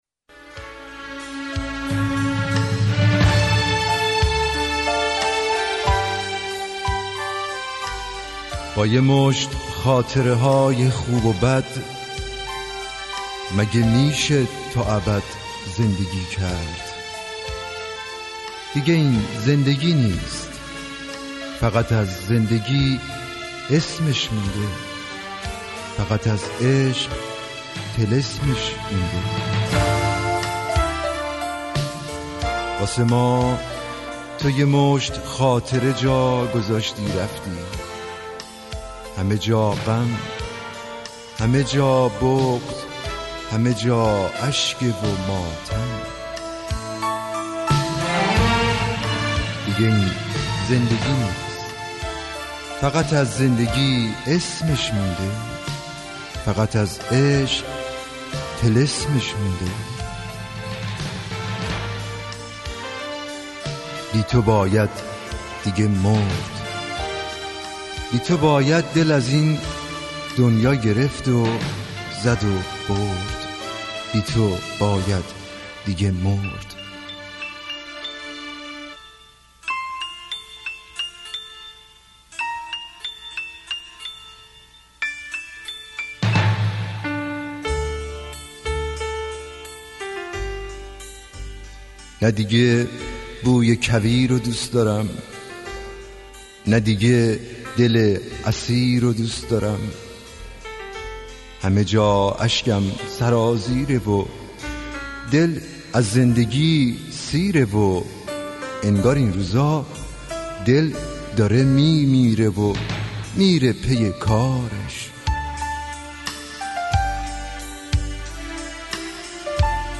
دکلمه قدیمی غمگین